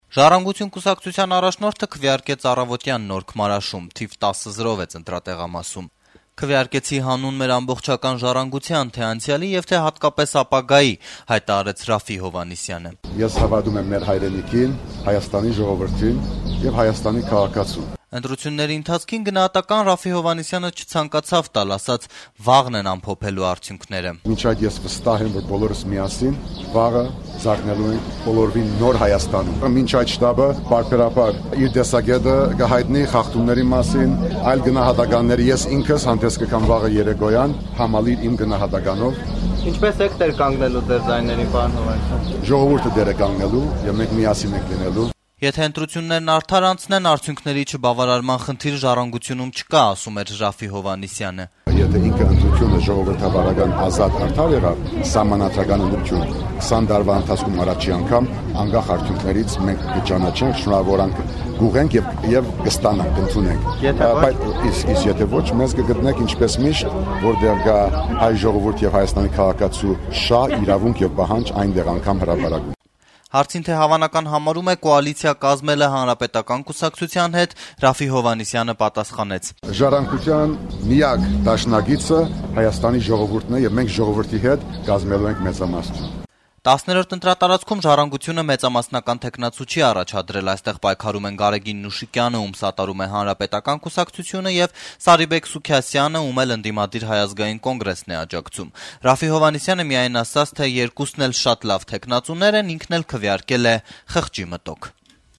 «Ժառանգության» միակ դաշնակիցը Հայաստանի ժողովուրդն է», - ընտրատեղամասում հայտարարեց կուսակցության առաջնորդը։